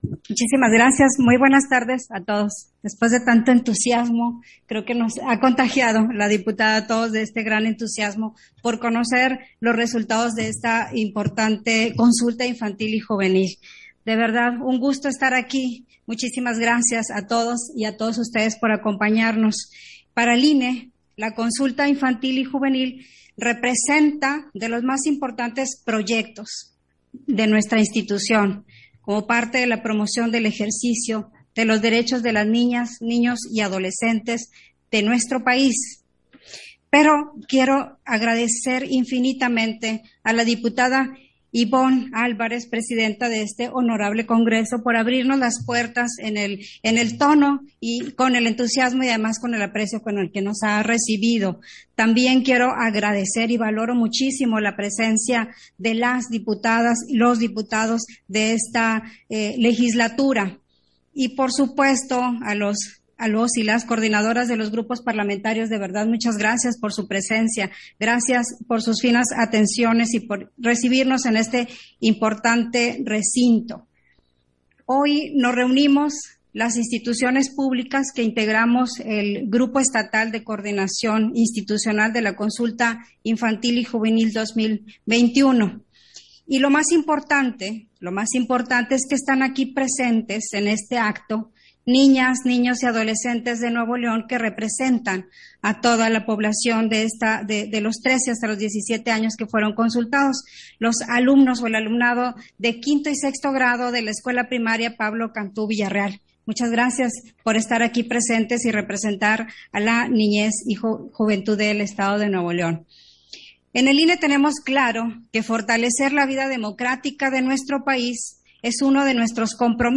Intervención de Olga Alicia Castro Ramírez, Vocal Ejecutiva de la Junta Local de Nuevo León, en la presentación de los resultados de la Consulta Infantil y Juvenil 2021 en el Estado